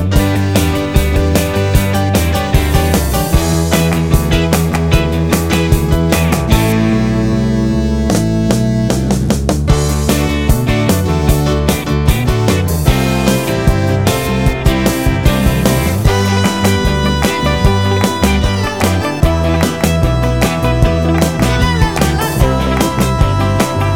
End Cut Down Pop (1970s) 3:46 Buy £1.50